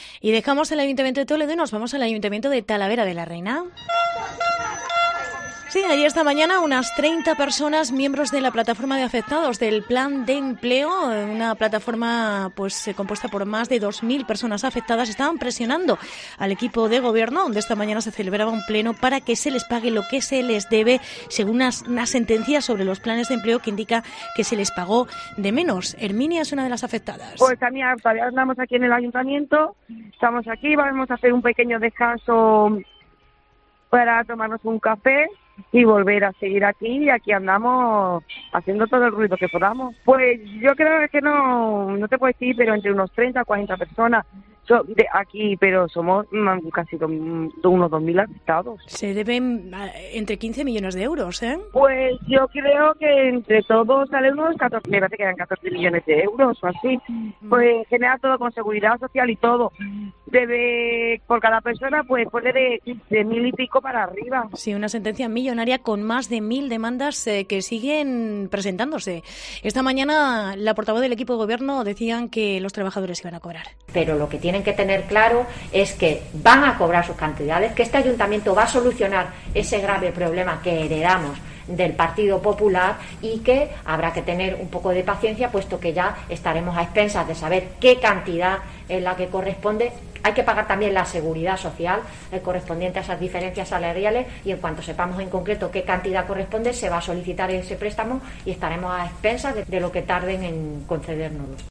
Reportaje manifestación Plataforma Planes de Empleo